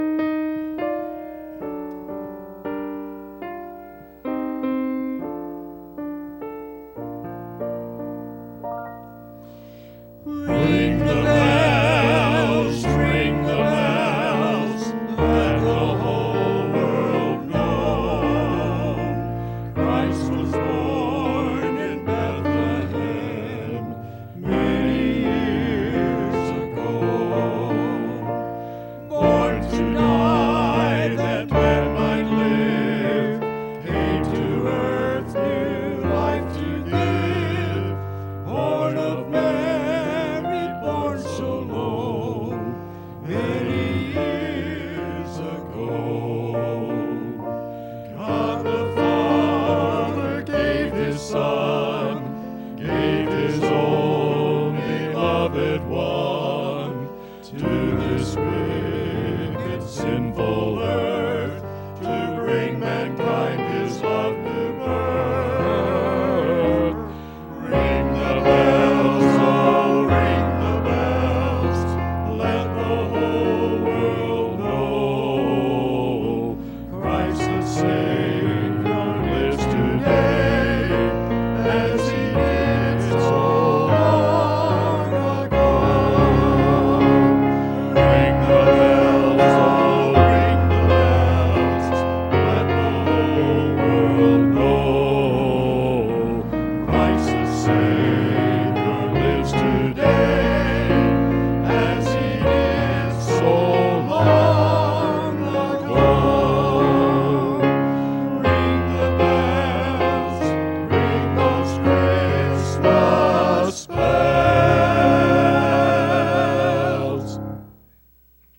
“Ring The Bells” – Men’s Quartet – Faith Baptist 2012